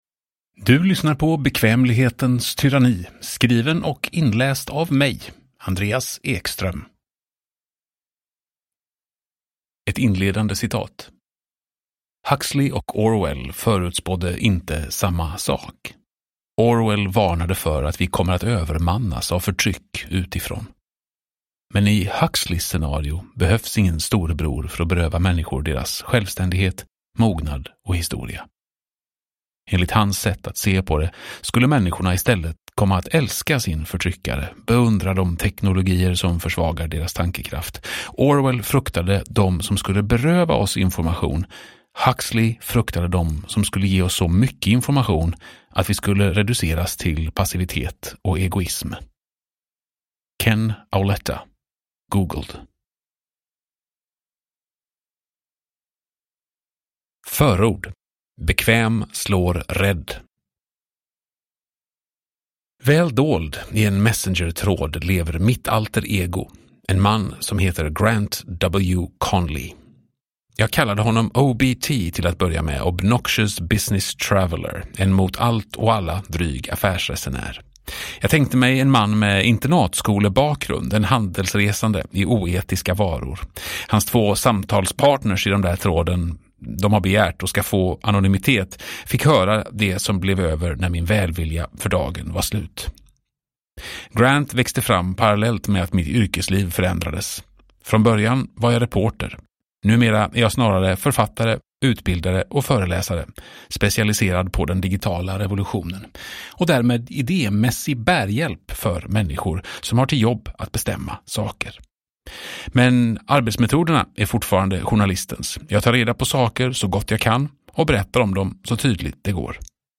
Bekvämlighetens tyranni : Hur vi förlorade makten över vår tids stora revolution – Ljudbok – Laddas ner